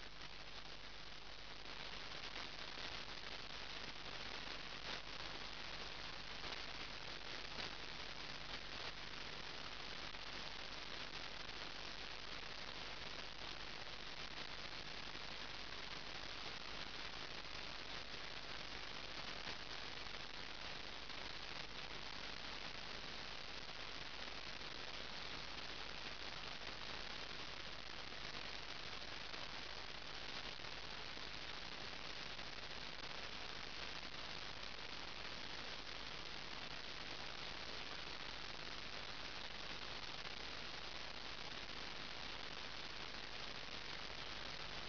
Греческая береговая станция Limnos (SVL) принята на частоте 2730 в 00:33 UTC
в середине передачи слышно женским голосом название Olimpia Radio